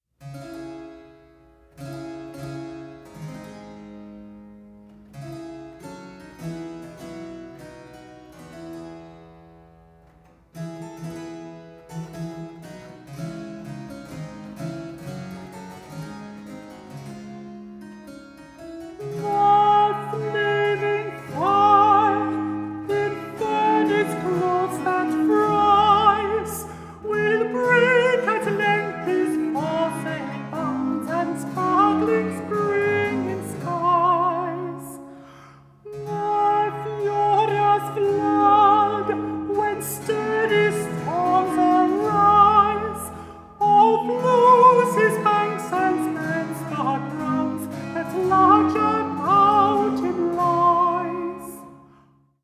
Stereo
bass viol
tenor viol
treble viol
virginal
Sixteenth Century Scottish Songs, Dances and Fancies